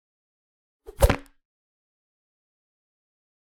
mandrake foundry13data/Data/modules/psfx/library/weapon-attacks/spear/v1